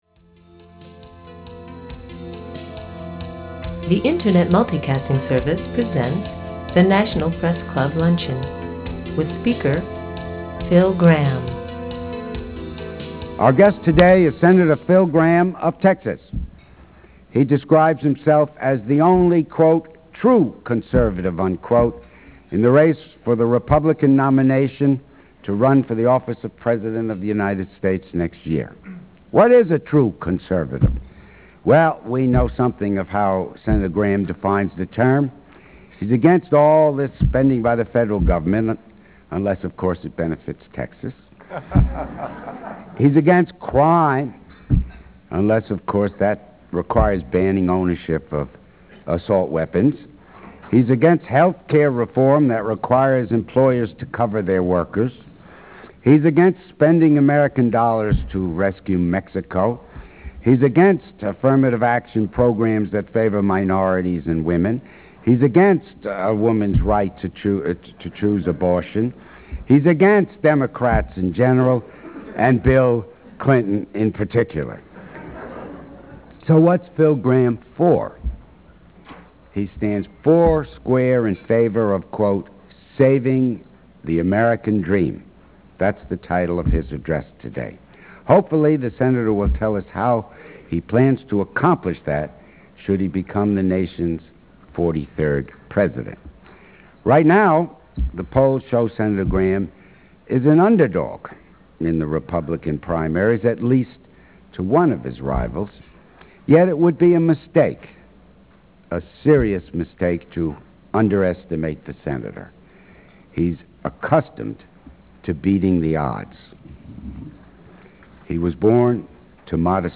.au format (19 Mb), .gsm format (4 Mb), .ra format (2 Mb) The National Press Club Luncheons are brought to you by the Internet Multicasting Service and our sponsors under an agreement with the National Press Club Board of Governors.